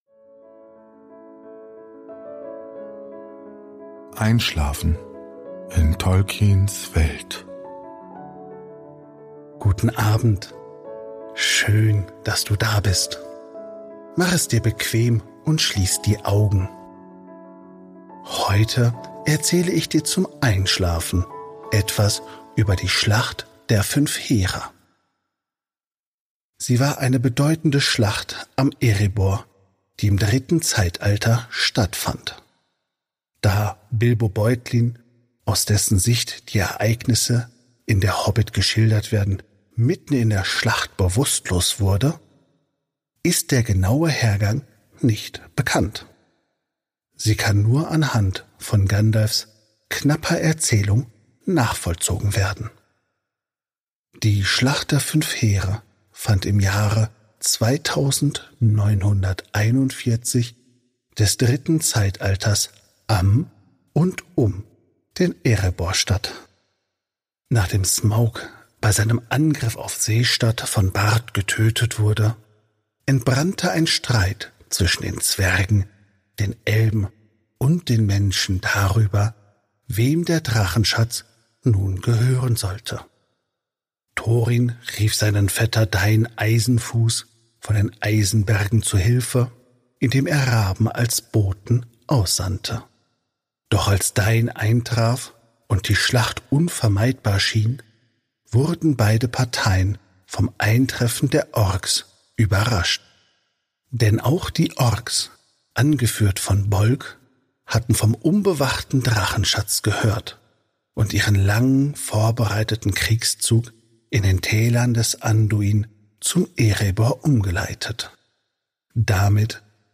Bildung Silmarillion Hobbit Mittelerde Ardapedia Auenland Productions Tolkien Herr der Ringe Einschlafen Einschlafhilfe Tolkien-stimmen Einschlafpodcast